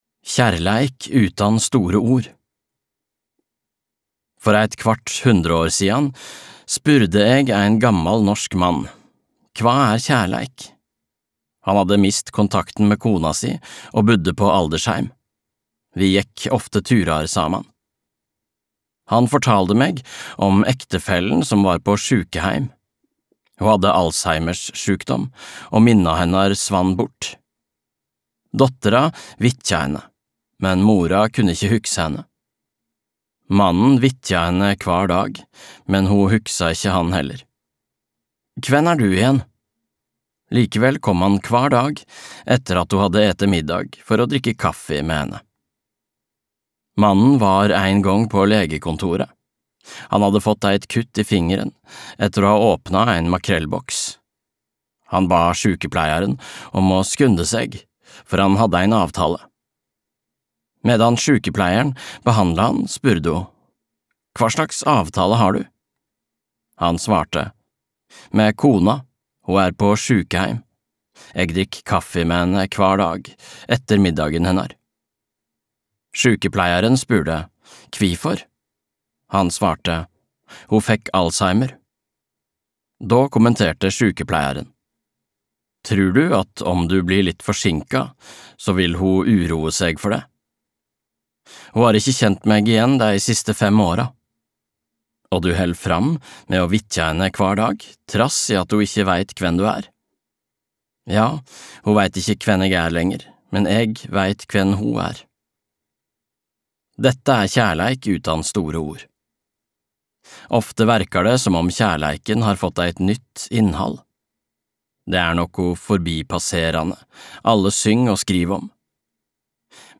En bok om demens - husk meg når jeg glemmer (lydbok) av Knut Engedal